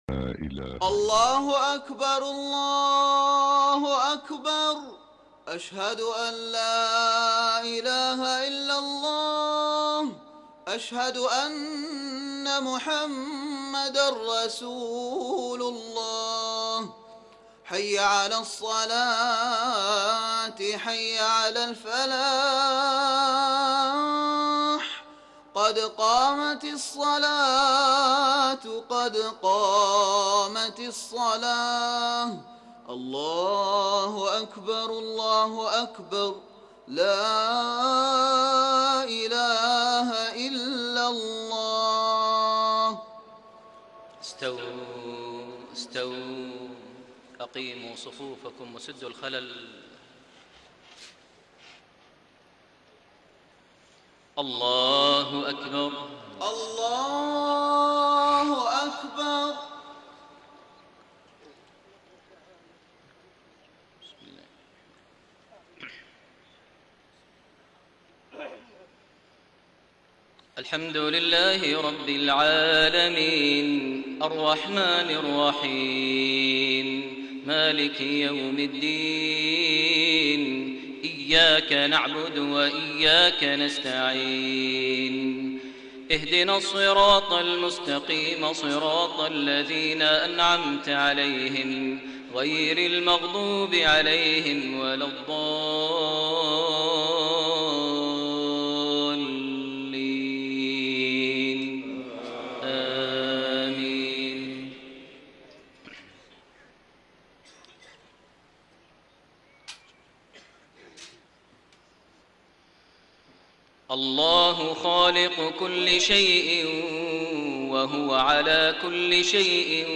صلاة المغرب 23 جمادى الآخرة 1433هـ من سورة الزمر 62-70 > 1433 هـ > الفروض - تلاوات ماهر المعيقلي